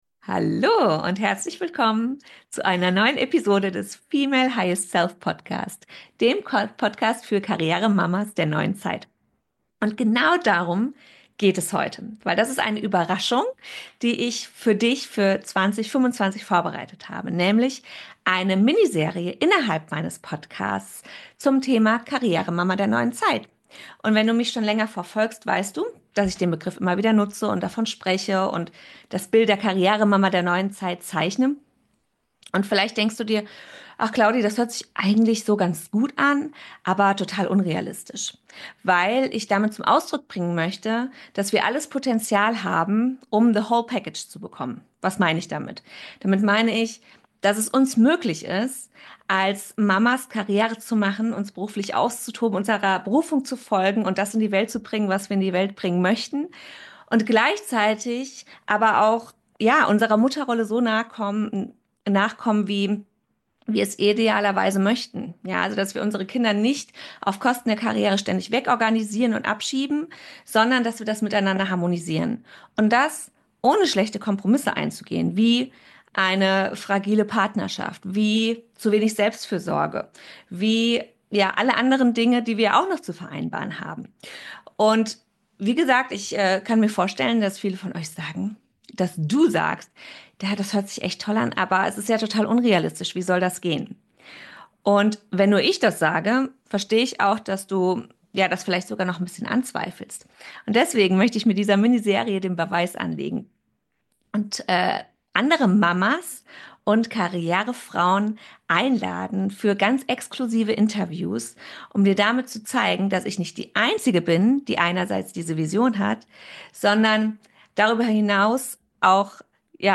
In diesem Interview teilt sie ihre wichtigsten Erkenntnisse darüber, wie du dein tägliches Glück im Alltag findest – und dein Leben nach deinen eigenen Regeln gestaltest.